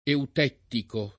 eutettico [ eut $ ttiko ]